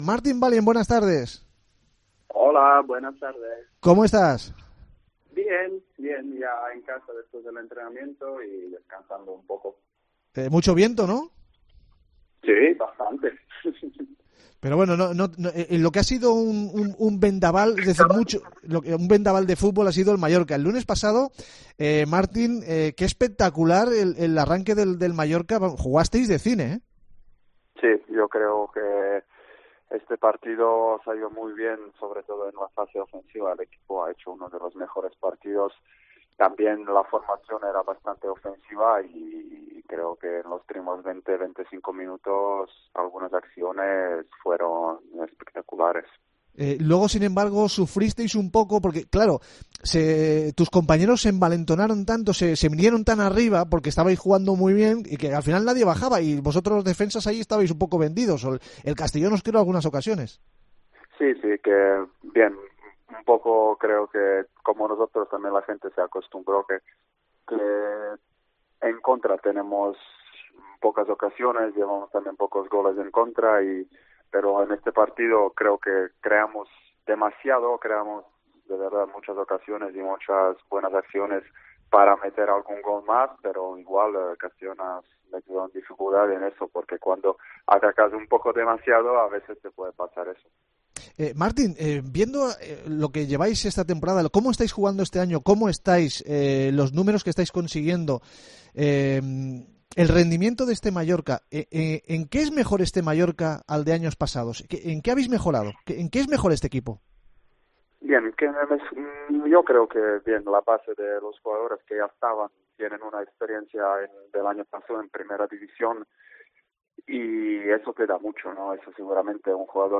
Hoy en Deportes Cope Baleares hablamos con Martin Valjent, que analiza la temporada del equipo de Luis García.